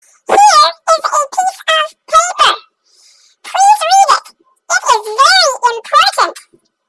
LittleGirl.mp3